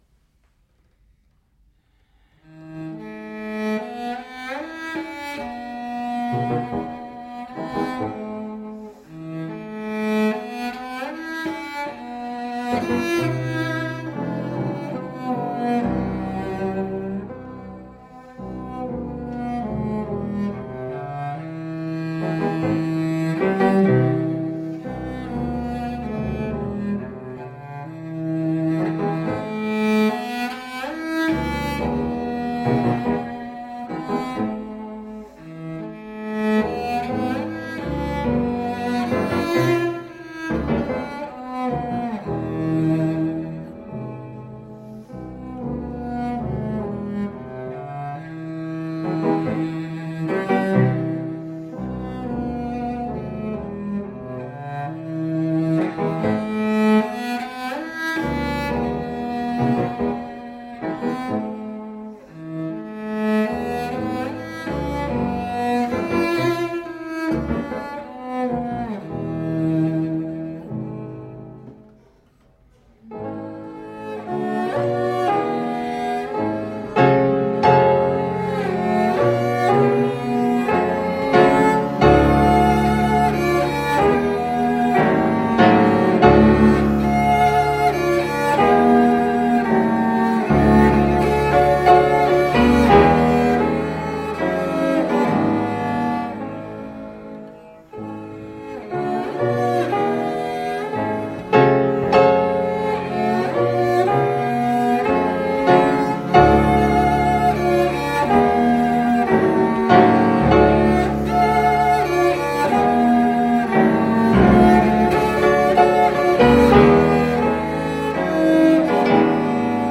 Baroque oboist extraordinaire.